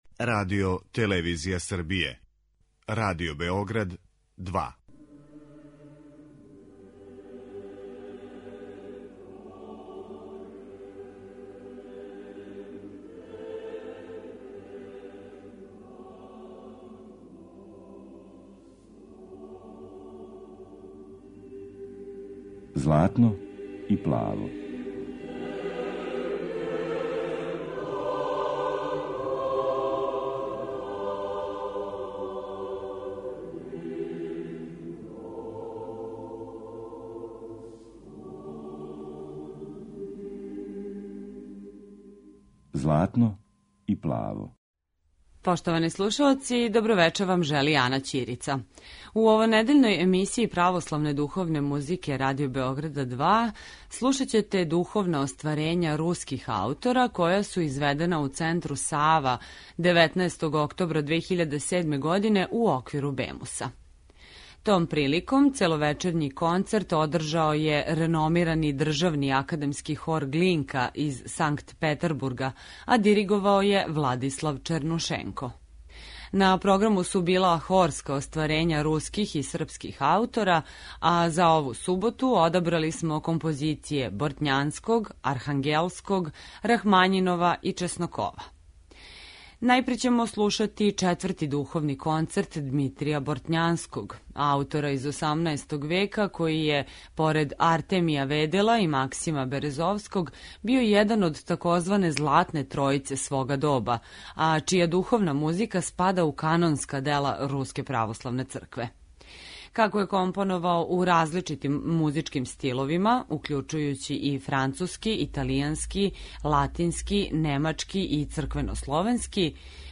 Хор „Глинка” на БЕМУС-у 2007.
Ове суботе емитујемо део целовечерњег концерта који је 2007. године у Центру „Сава" одржао Државни академски хор „Глинка" из Санкт Петербурга под управом Владислава Чернушенка.